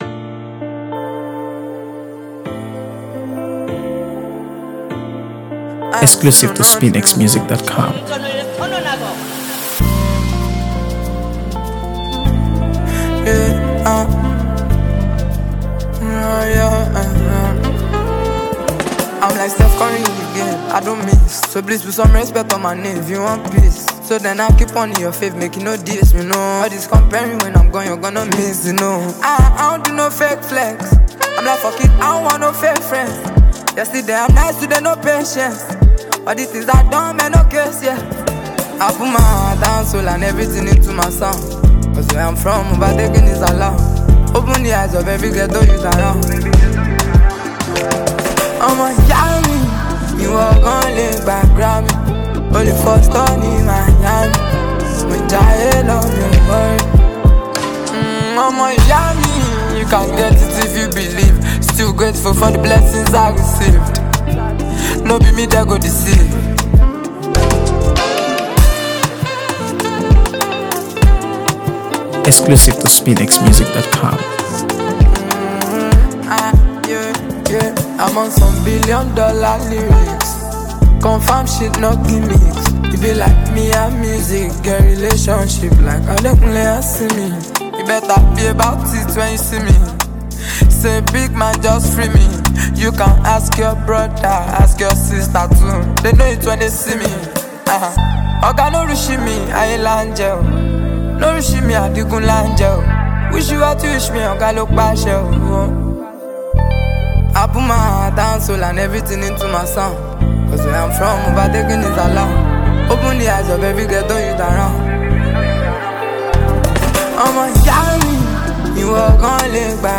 AfroBeats | AfroBeats songs
smooth vocal delivery
an irresistible Afro-fusion beat
is clean and dynamic